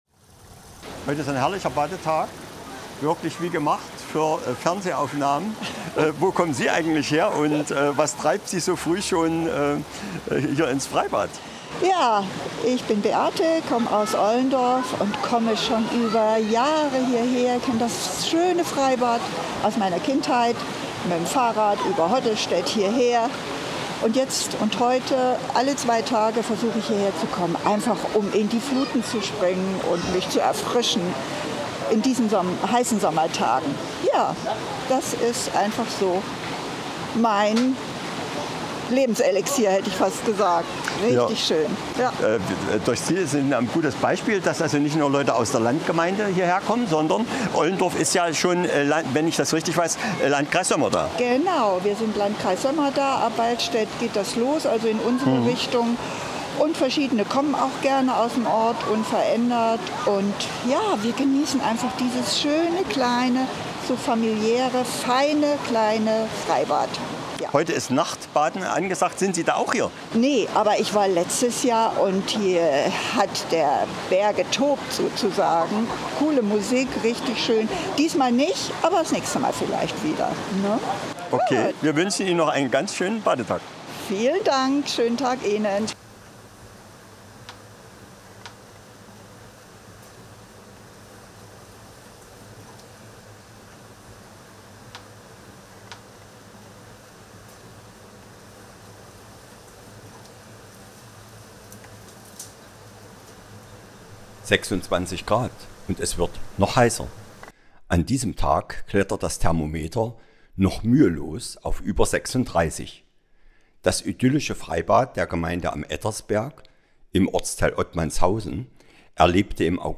Teil zwei der Stra�enumfrage zu diesem Thema auf dem Erfurter Anger.